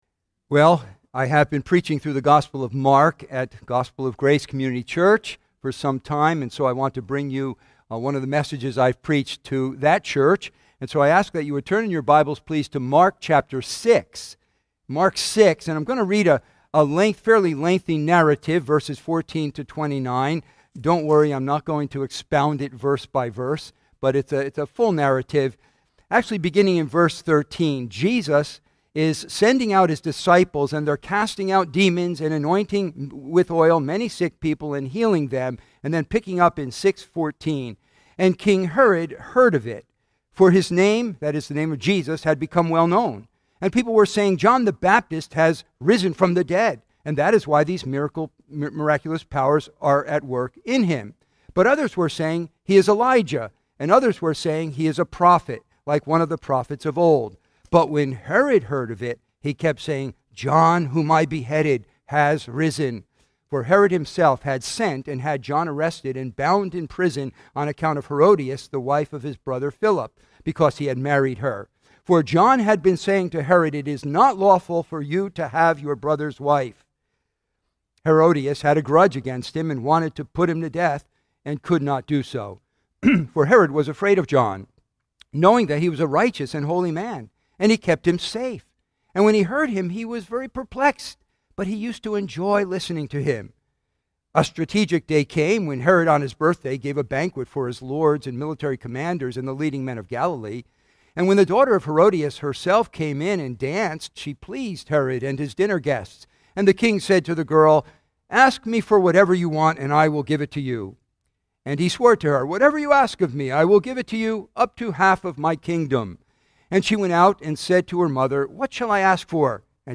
Sermons – Redeemer Community Church